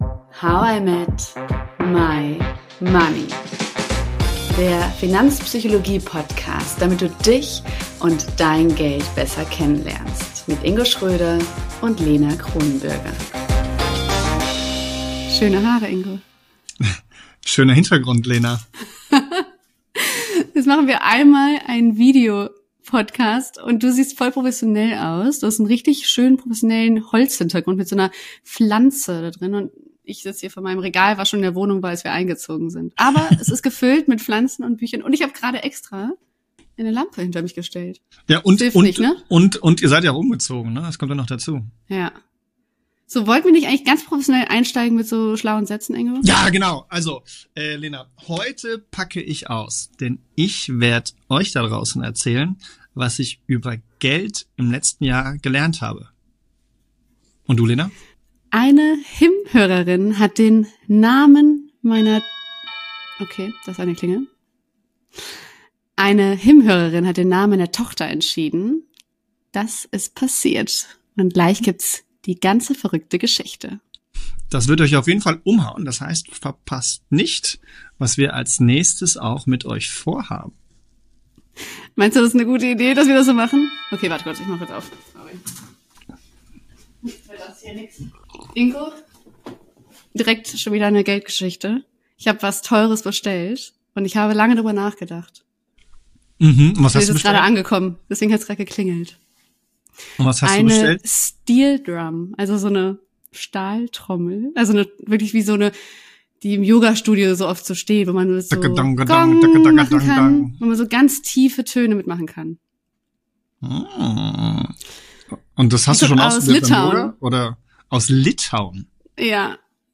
Zurück aus der Babypause, direkt ins Studio – und mit einem echten Knall!